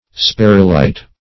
Search Result for " sperrylite" : The Collaborative International Dictionary of English v.0.48: Sperrylite \Sper"ry*lite\, n.[Named after F. L. Sperry, who discovered it.] (Min.) An arsenide of platinum occuring in grains and minute isometric crystals of tin-white color.